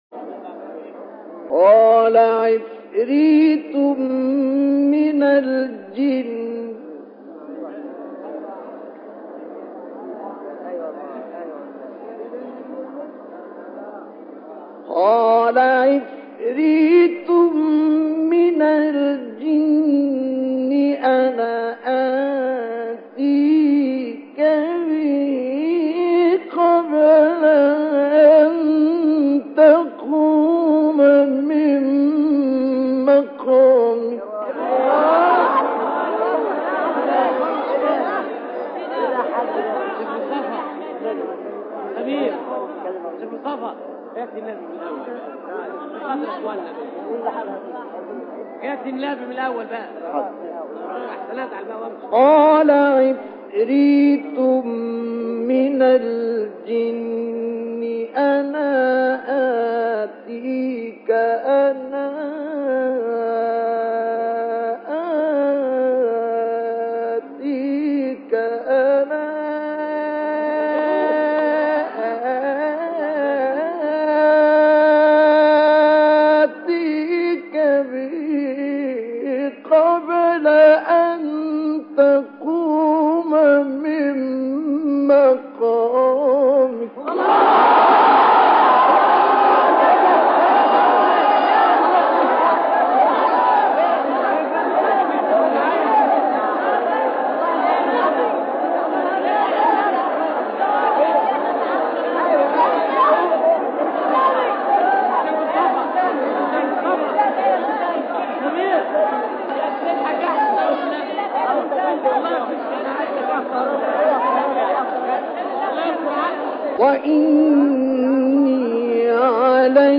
به گزارش ایکنا، چهار اجرای متفاوت از تلاوت آیه 39 سوره مبارکه نمل با صوت مصطفی اسماعیل، قاری قرآن کریم از کشور مصری در کانال تلگرامی اکبرالقراء منتشر شده است.
تلاوت آیه 39 سوره نمل در سال 1985 میلادی